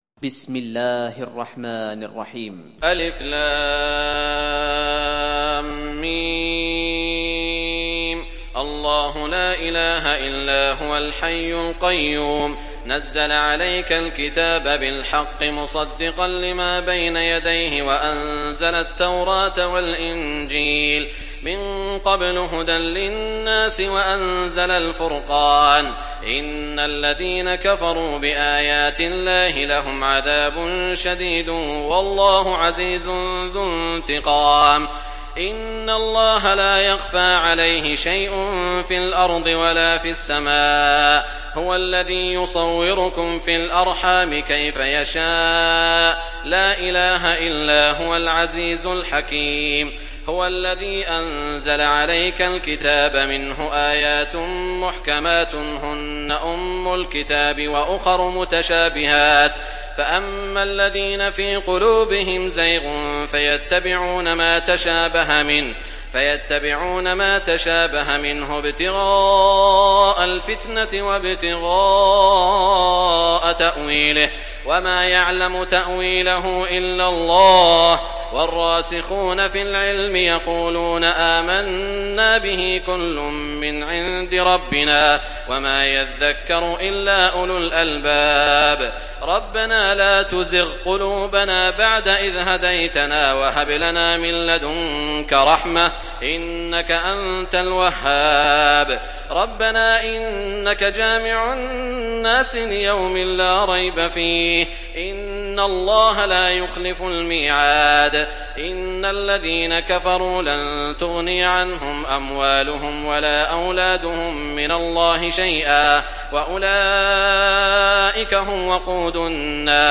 Surat Al Imran mp3 Download Saud Al Shuraim (Riwayat Hafs)